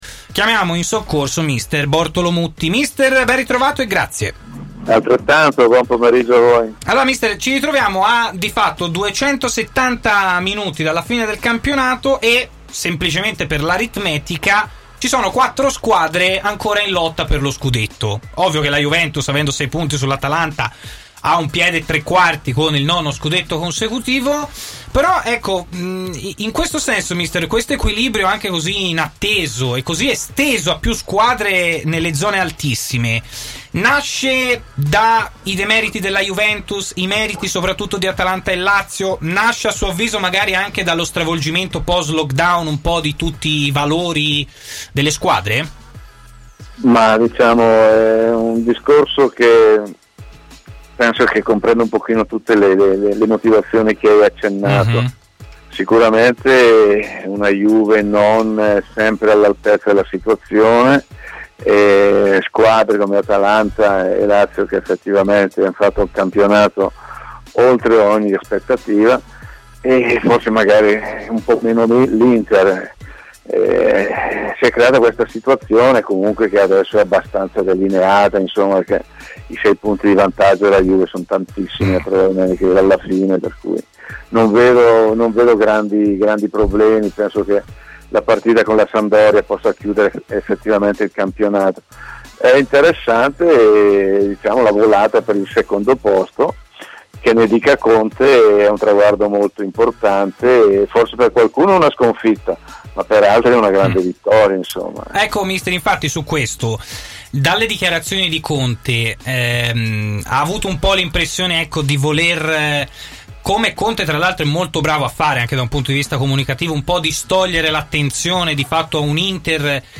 L'allenatore Bortolo Mutti ha parlato intervenendo in diretta a TMW Radio, nel corso della trasmissione Stadio Aperto.